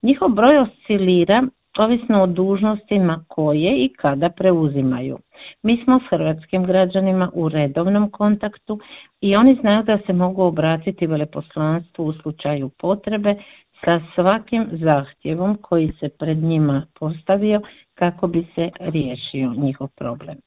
telefonskom Intervjuu Media servisa
hrvatsku veleposlanicu iz Kijeva Anicu Djamić